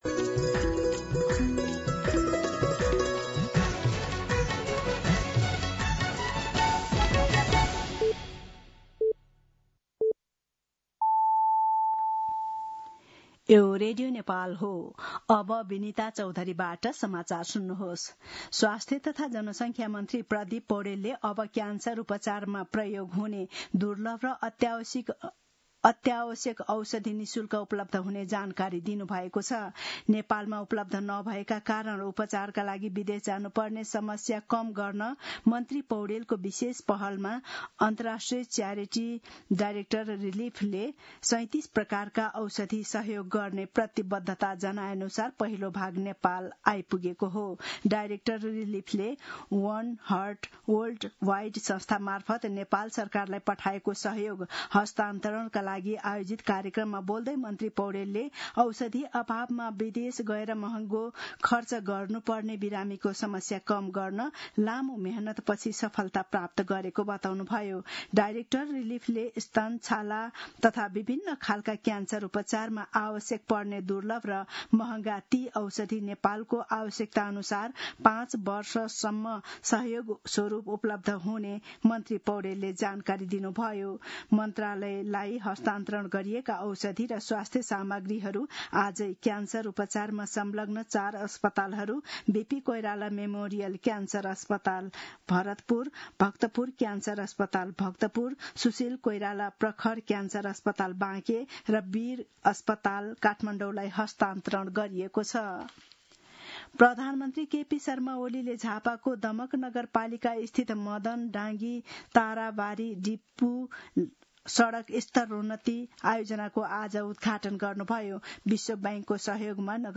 दिउँसो १ बजेको नेपाली समाचार : २९ असार , २०८२